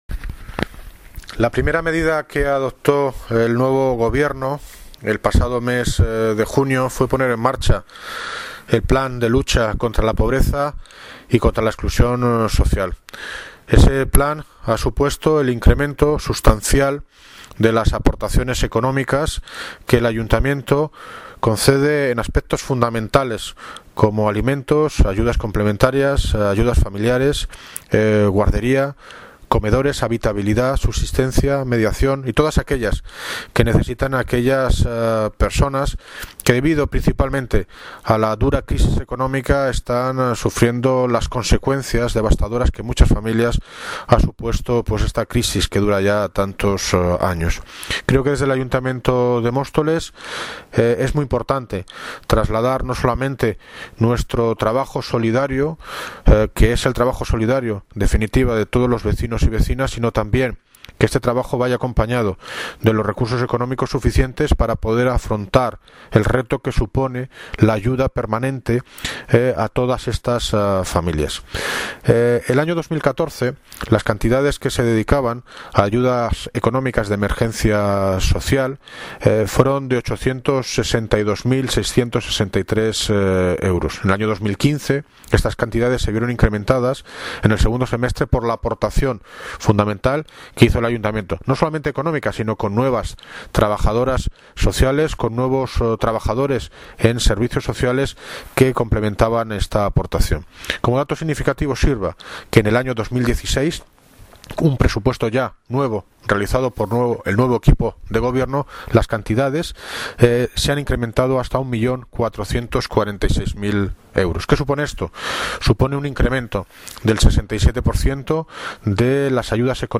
Audio - David Lucas (Alcalde de Móstoles) Sobre Incremento ayudas sociales